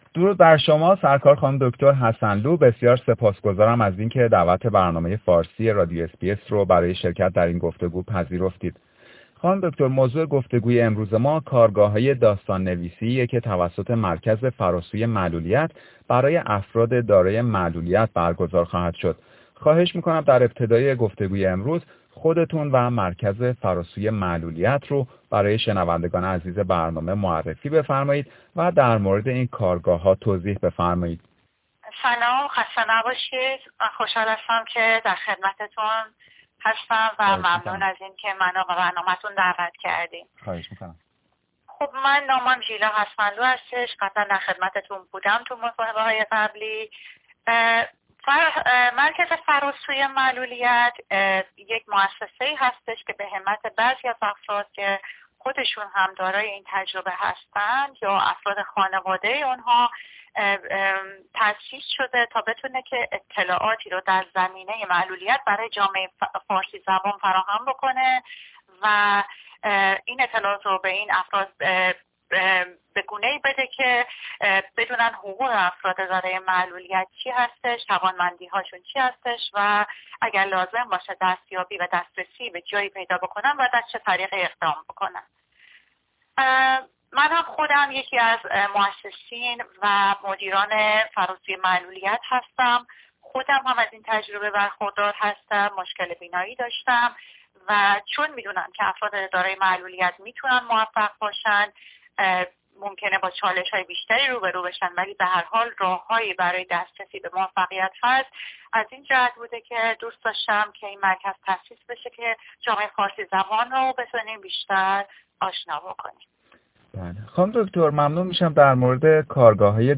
برنامه فارسی رادیو اس بی اس گفتگویی داشته